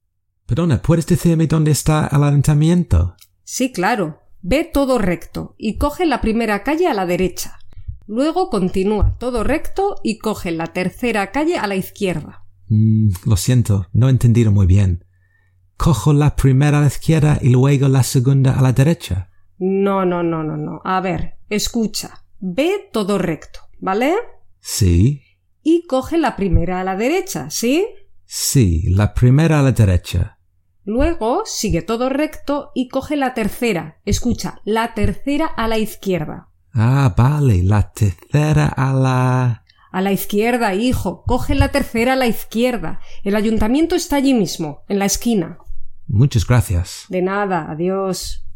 11.-Listening-Practice-Imperatives-Part-1.mp3